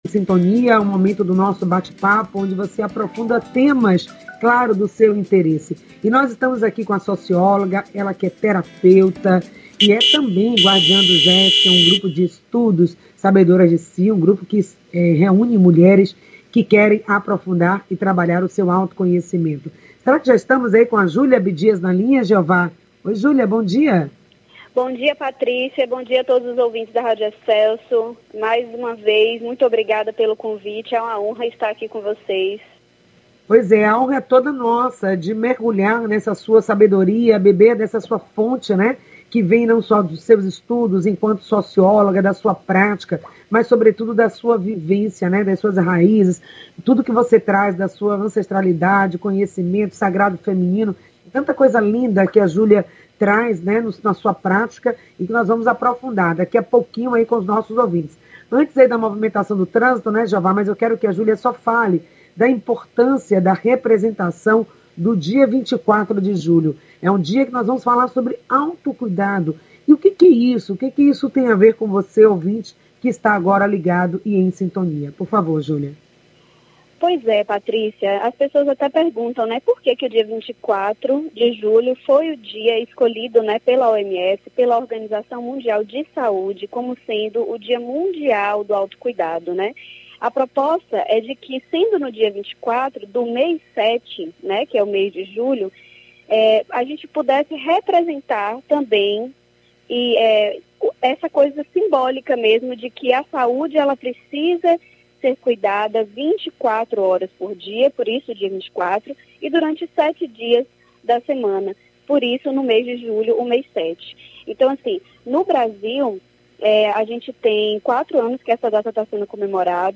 O Programa – Em Sintonia desta Terça-feira, (19.07) convida você a refletir sobre: PICS – Práticas Integrativas e complementares, tendo como Tema: PICS e o auto cuidado. A entrevista